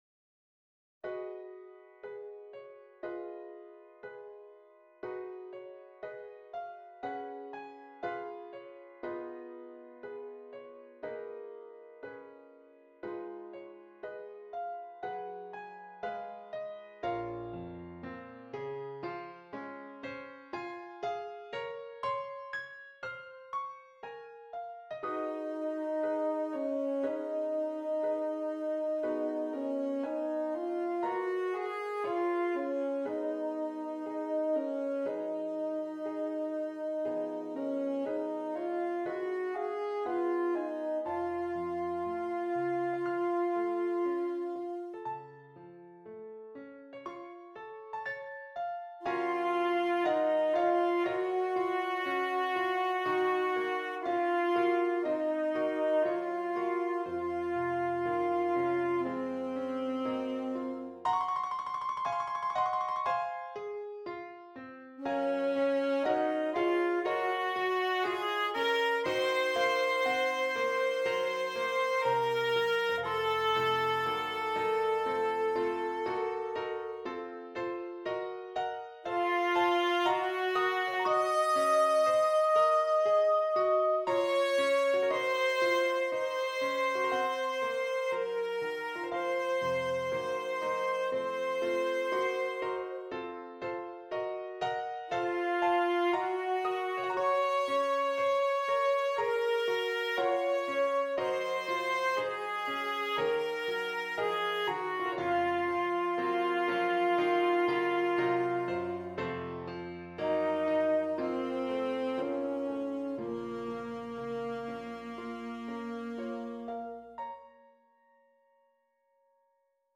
Alto Saxophone and Keyboard